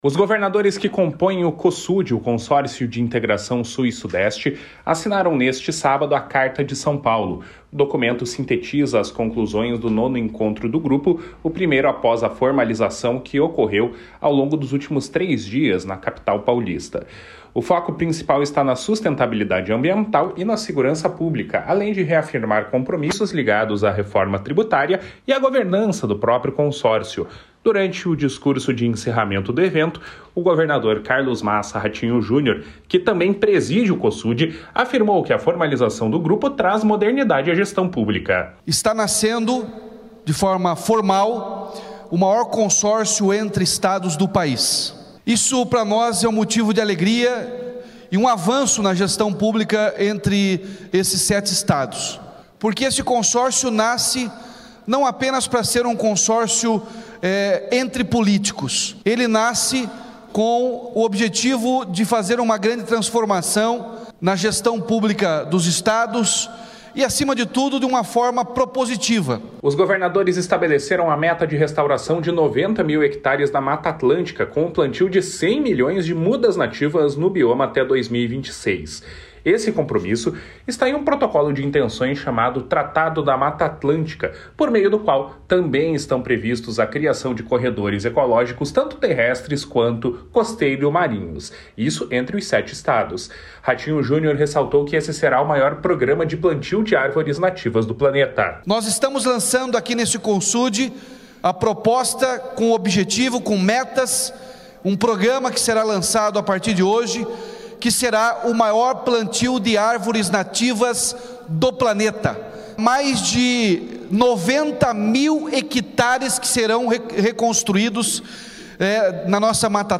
Encerramento da reunião do Cosud.mp3